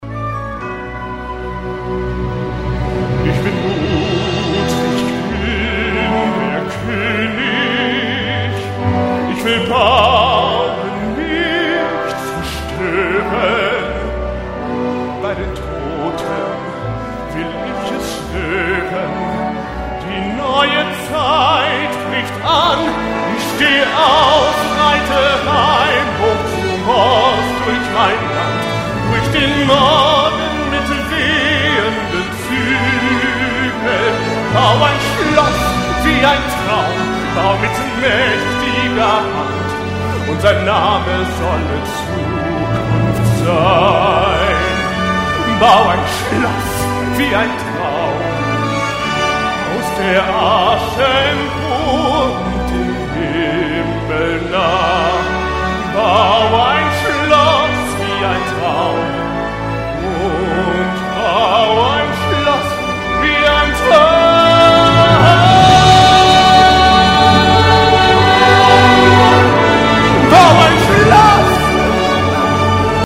Live- Aufnahme